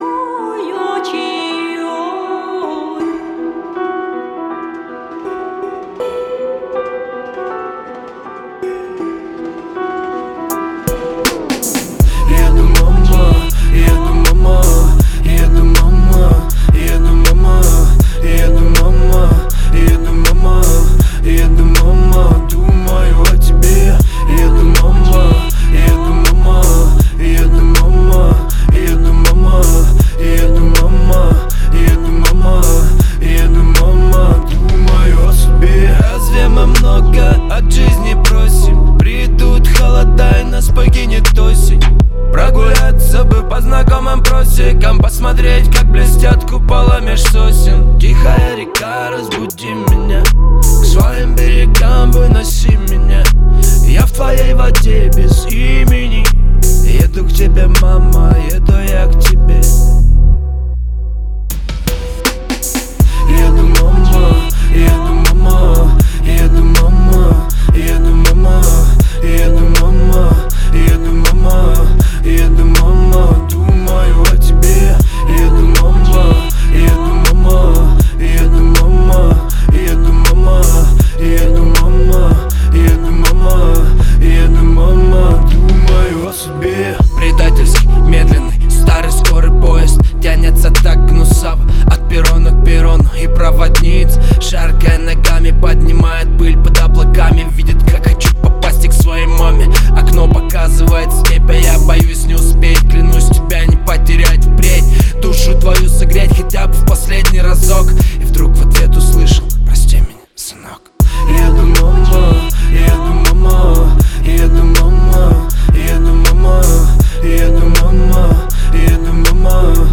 это энергичная и зажигательная песня в жанре поп-музыки